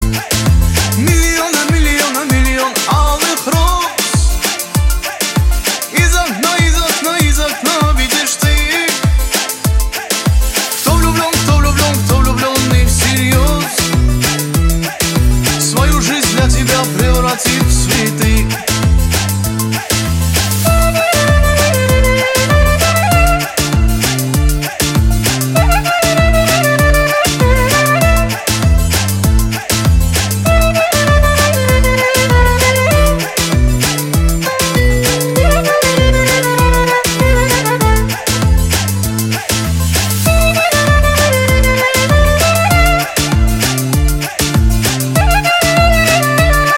• Качество: 320, Stereo
поп
восточные
романтичные
кавер
армянские
Восточный кавер